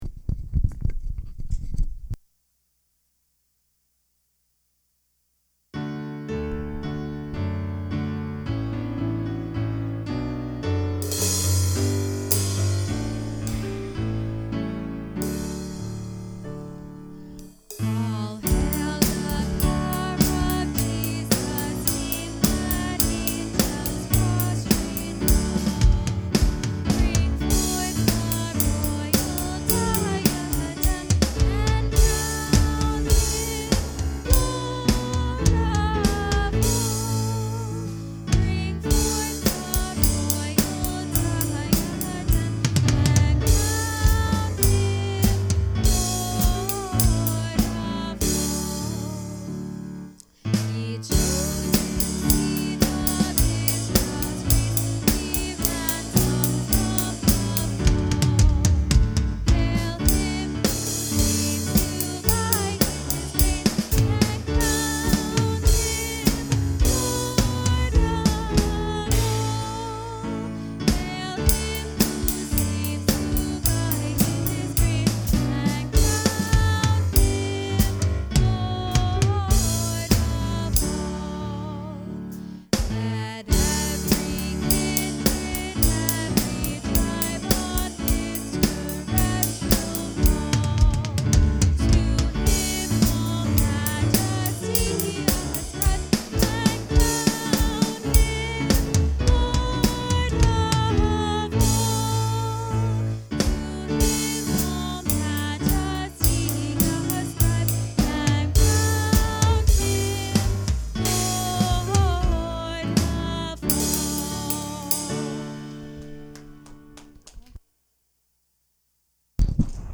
Acts 28:1-10 Service Type: Sunday Morning Worship Intro